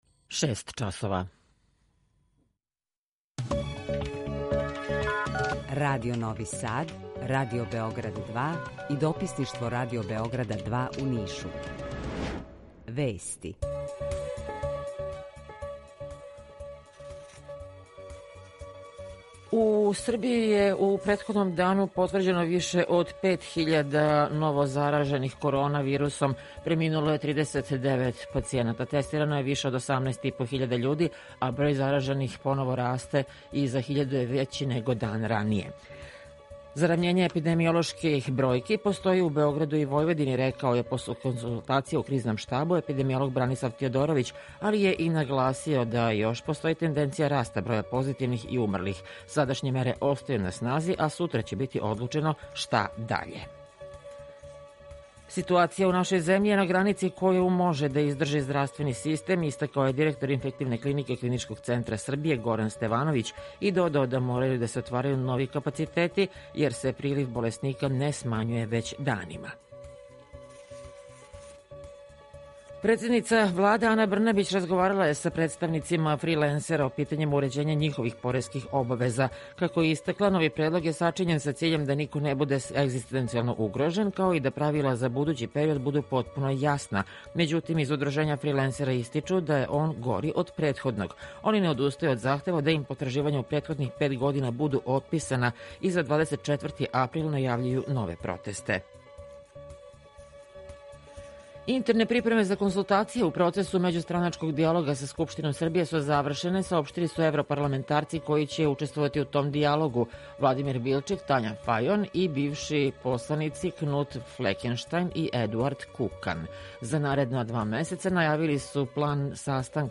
Укључење Радија Косовска Митровица
Јутарњи програм из три студија
У два сата, ту је и добра музика, другачија у односу на остале радио-станице.